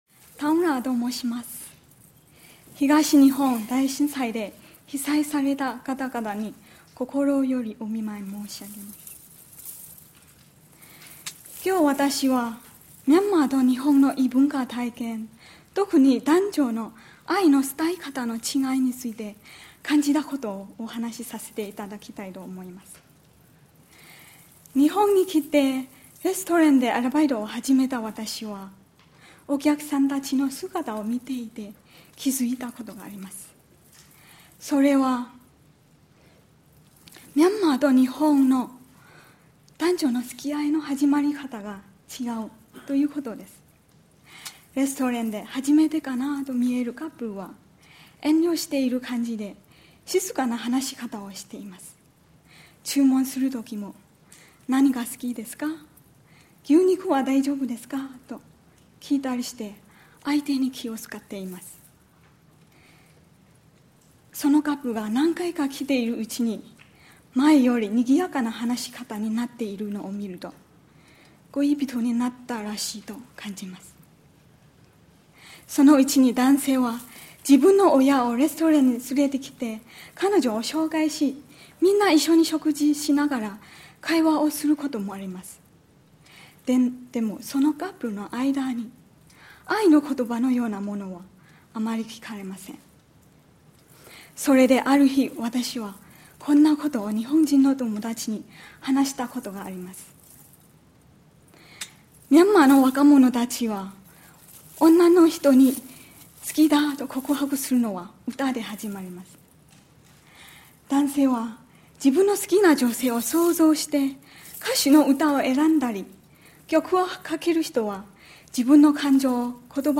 「外国人による日本語弁論大会」
第52回 2011年6月11日 桜美林大学　町田キャンパス　太平館「レクチャーホール」（東京都）